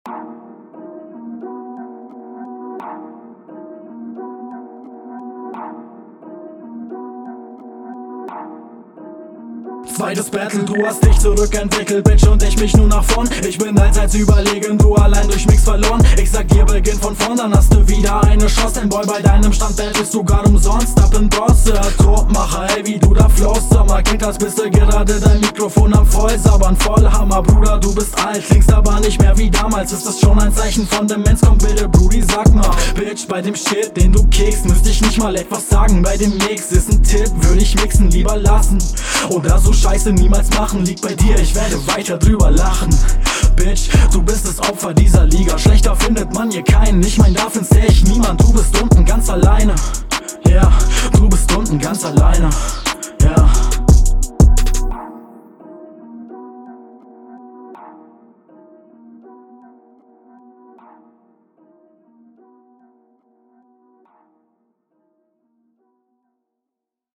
Flow ist schon sehr nice geht mir richtig gut rein mische auch sehr sauber sehr …
cooler rap, komischer aber cooler sound, lines schön von oben herab, sehr cool geflowt